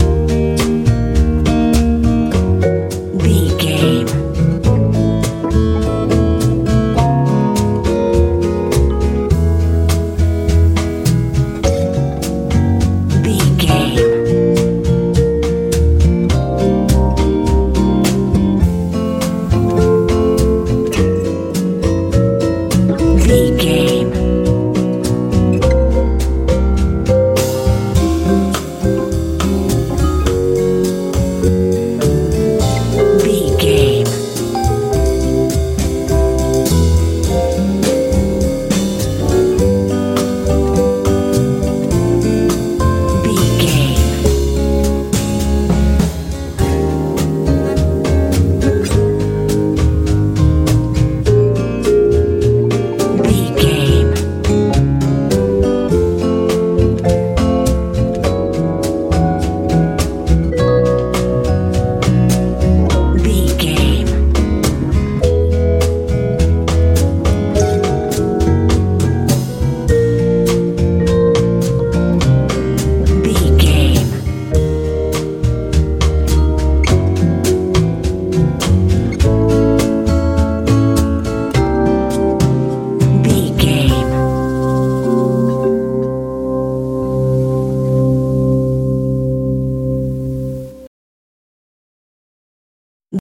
dream pop feel
Ionian/Major
E♭
light
mellow
organ
acoustic guitar
bass guitar
drums
80s
90s